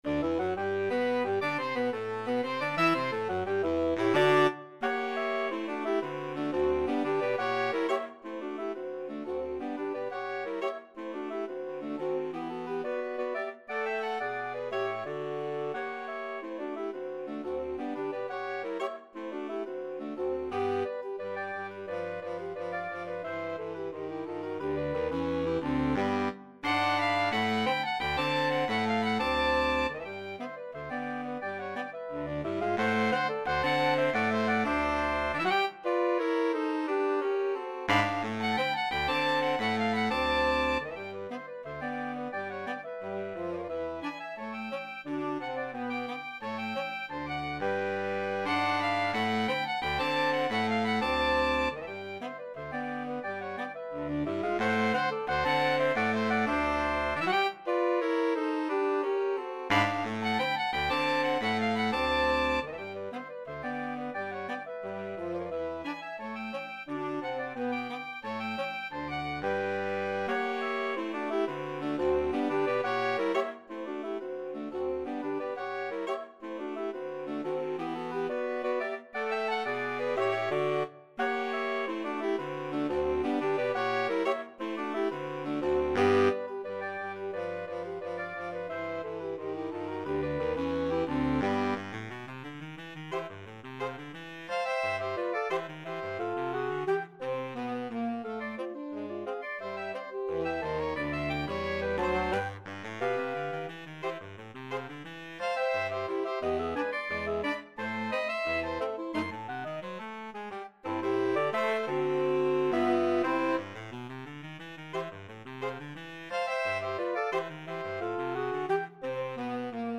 Slow March Tempo =88
2/4 (View more 2/4 Music)
Classical (View more Classical Saxophone Quartet Music)